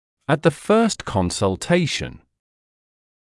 [æt ðə fɜːst ˌkɔnsl’teɪʃn][эт зэ фёːст ˌконсл’тэйшн]на первой консультации